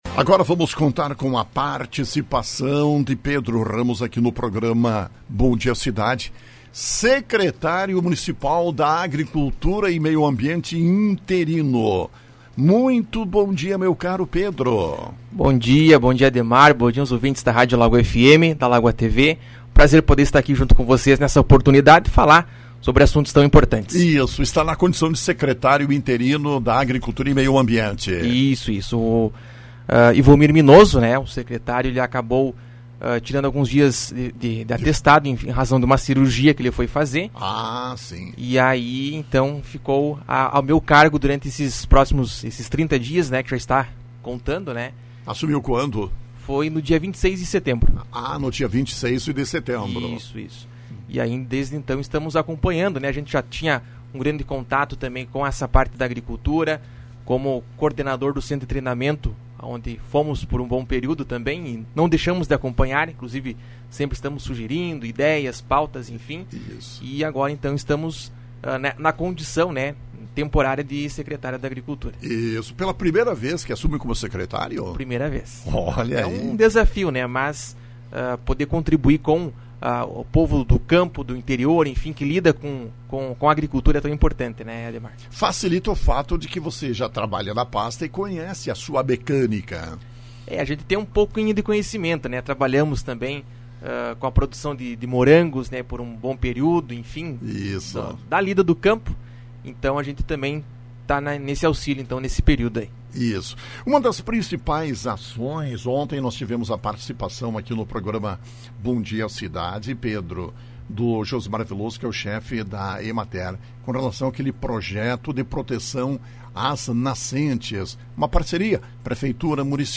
Assumiu, interinamente, a pasta Pedro Ramos. Na manhã desta terça-feira concedeu entrevista à Rádio Lagoa FM.